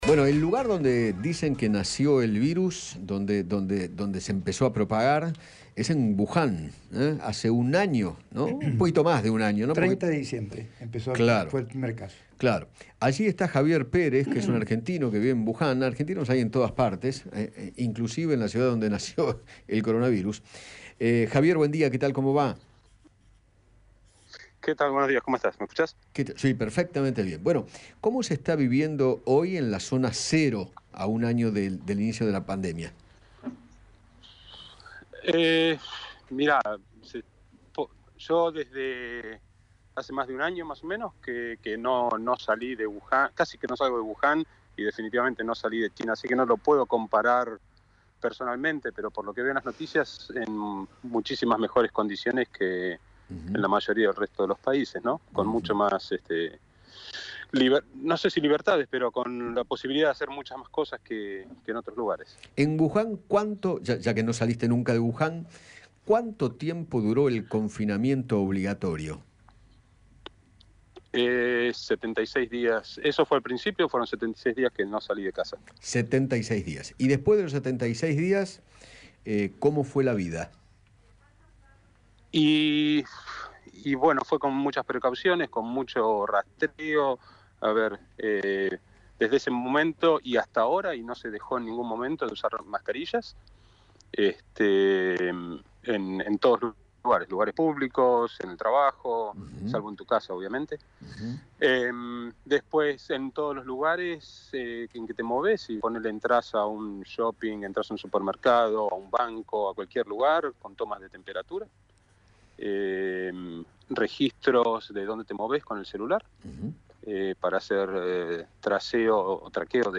dialogó con Eduardo Feinmann sobre la situación epidemiológica en aquella ciudad y se refirió a las medidas preventivas que se tomaron.